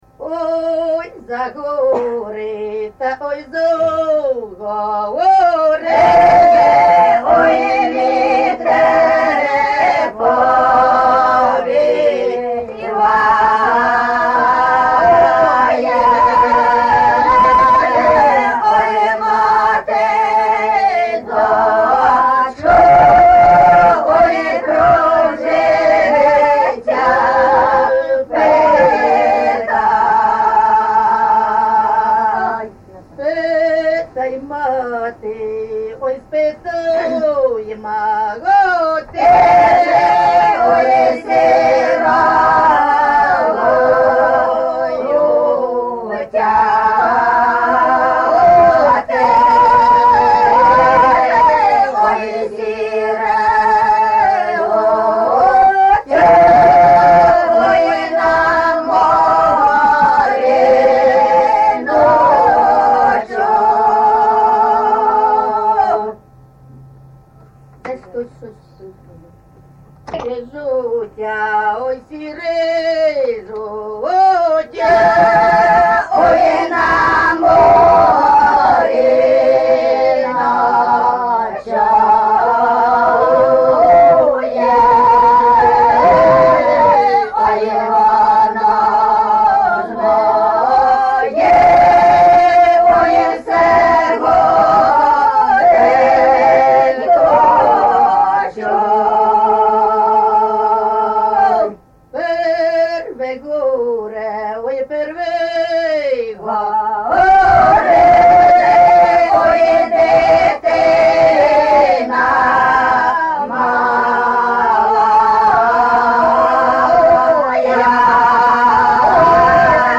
ЖанрПісні з особистого та родинного життя
МотивНещаслива доля, Родинне життя, Журба, туга
Місце записус. Лука, Лохвицький (Миргородський) район, Полтавська обл., Україна, Полтавщина